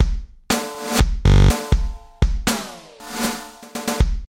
ここではエフェクト・コマンドの Bx, Rx, 0Sxx, 0Dxx, 等を使いました。
最初のサンプルを駆使した新たなフレーズ・サンプル（MP3）